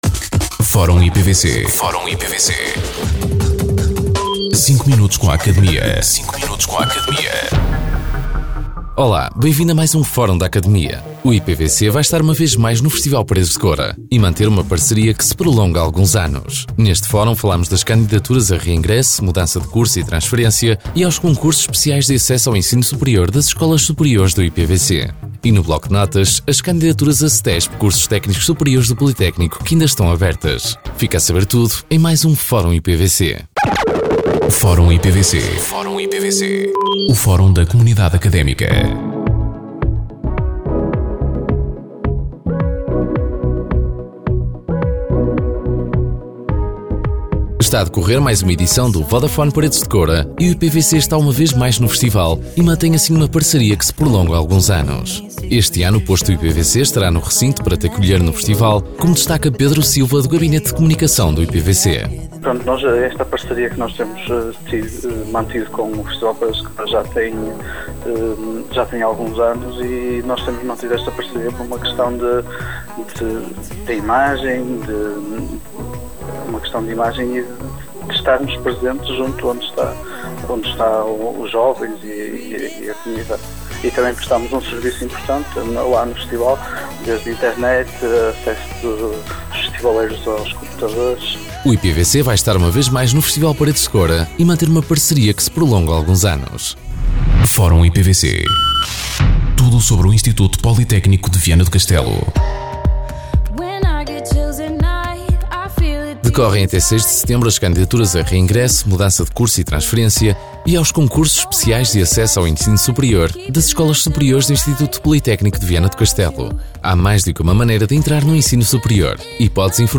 Depois do programa “Academia”, surge assim o “Fórum IPVC”, uma rubrica semanal de cinco minutos, em que são abordadas as notícias e factos mais relevantes da nossa instituição.
Entrevistados: